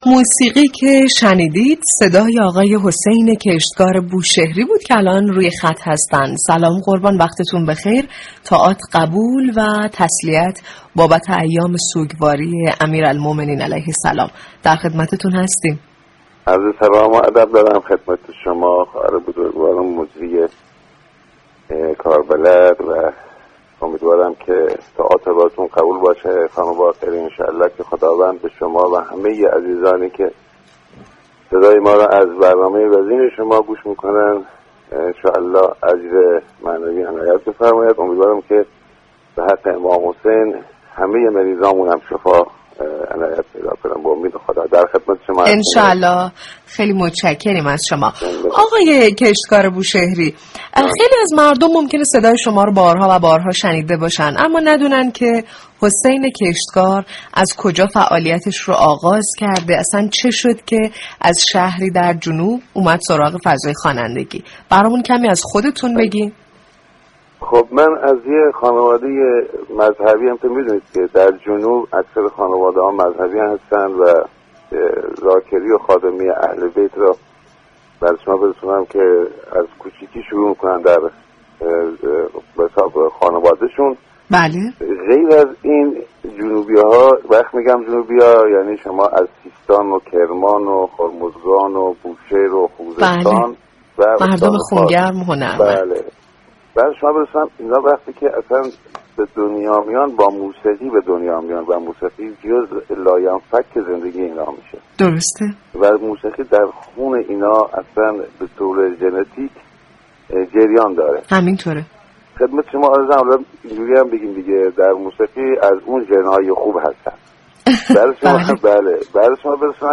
به گزارش روابط عمومی رادیو صبا، برنامه زنده «نواهنگ » برنامه موسیقی محوری است كه به پخش ترانه ها و موسیقی های درخواستی مخاطبان اختصاص دارد.
«نواهنگ» با پخش ترانه های درخواستی مناسب روزهای سوگواری و پرداختن به موسیقی در فضایی آرام و صمیمی با بخش های متنوع «تكمیل شعر، تقویم موسیقیایی و مهمان ویژه» همراه مخاطبان می شود.